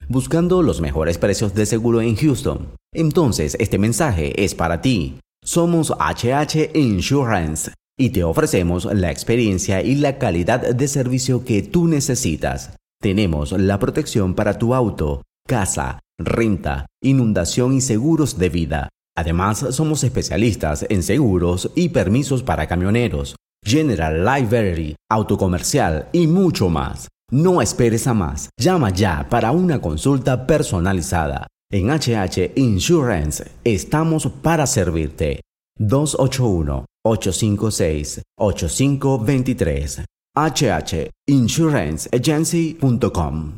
Grabacion de voz en off para video corporativo.